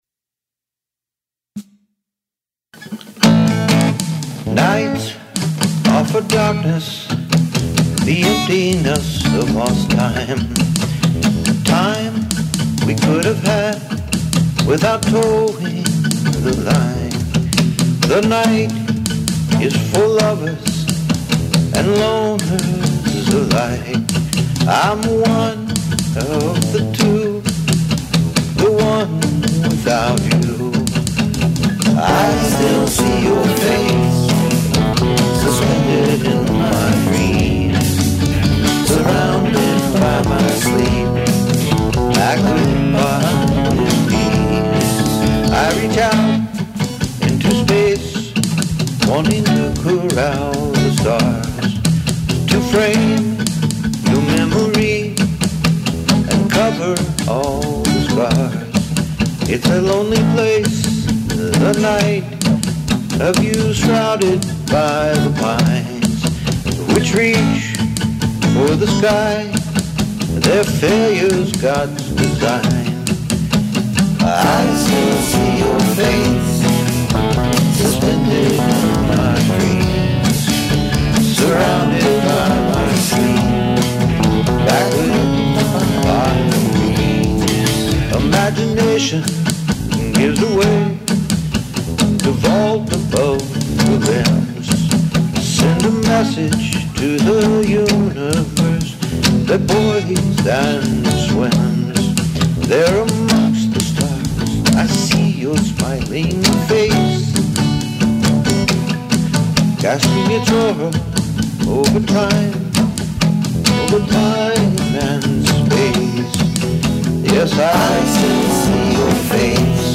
I decided to stick with the original vocal and guitar take, recorded sitting on the bed using my iPad. The drums were replaced, Ric licks added, and some harmony vocals.
Also added a new bass part.